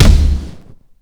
xplosion5.WAV